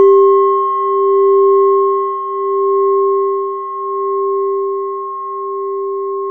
Index of /90_sSampleCDs/E-MU Formula 4000 Series Vol. 4 – Earth Tones/Default Folder/Japanese Bowls